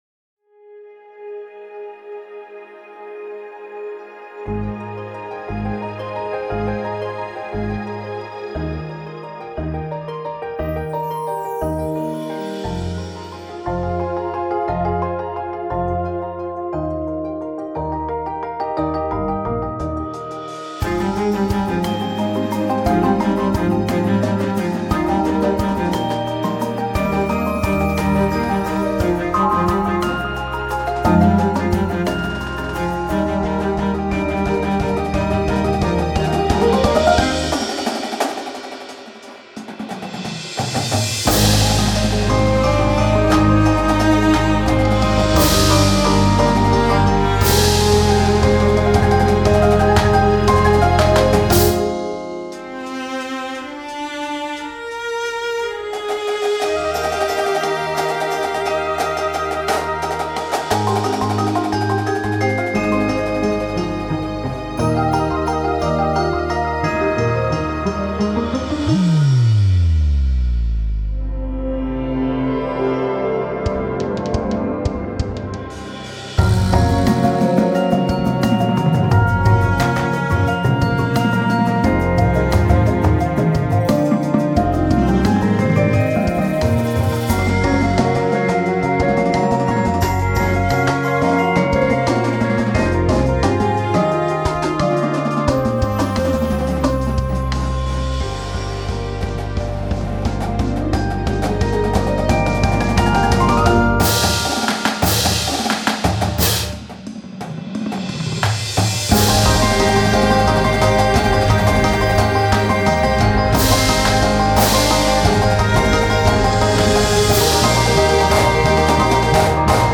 Instrumentation: Full Ensemble Percussion and Cello